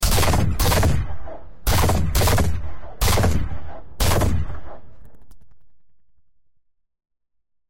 Laser Blasts High-energy weapon sounds "Sharp laser beam with sizzling charge-up and quick crackle"